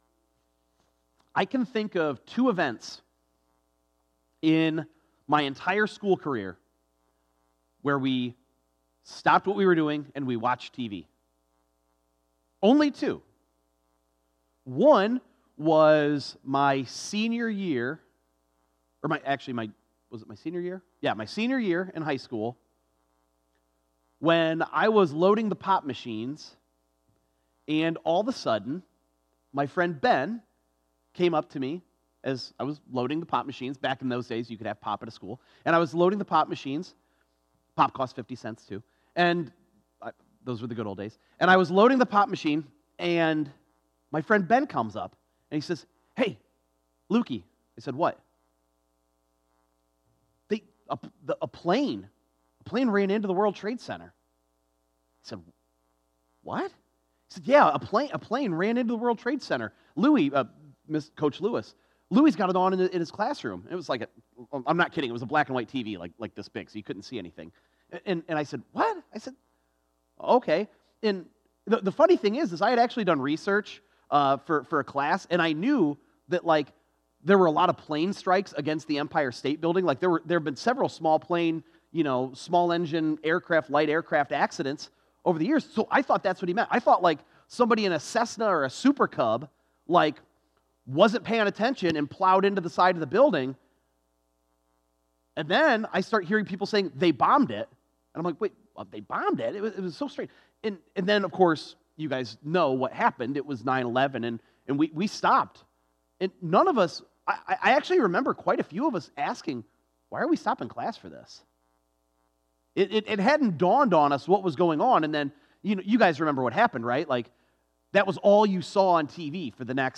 4_28_24_sunday_sermon.mp3